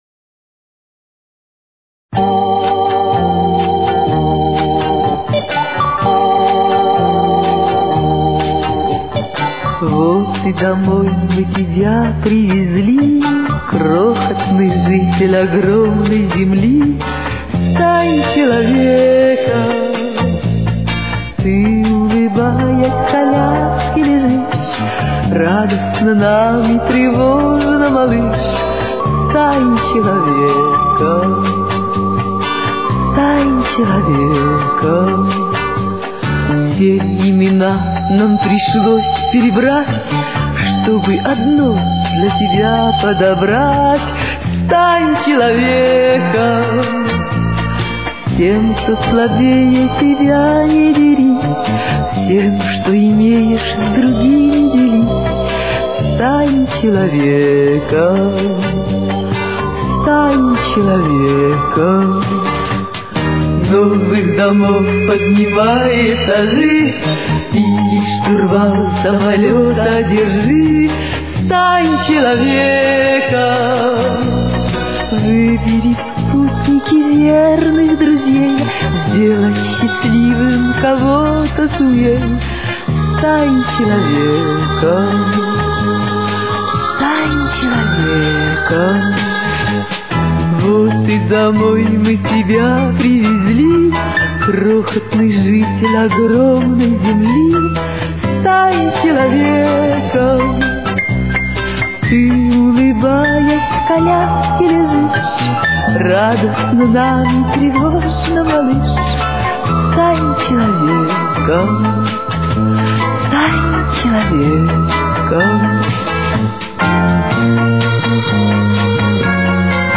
Соль минор. Темп: 126.